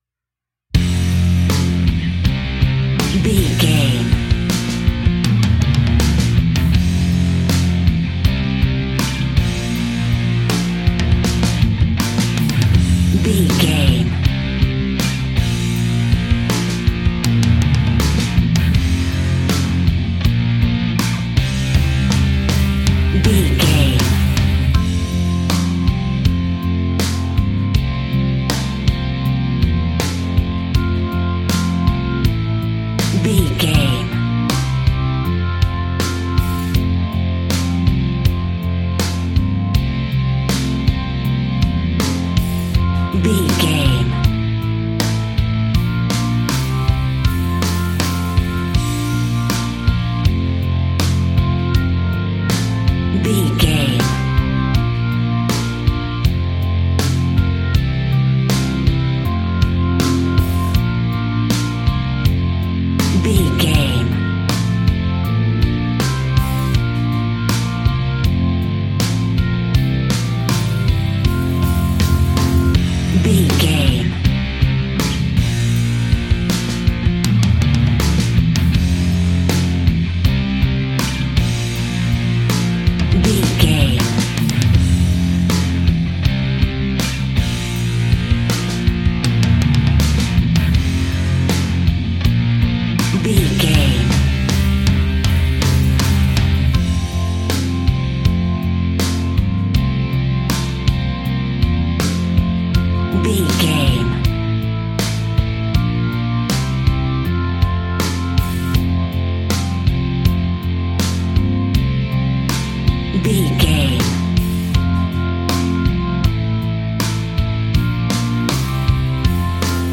Fast paced
Ionian/Major
hard rock
blues rock
distortion
Rock Bass
heavy drums
distorted guitars
hammond organ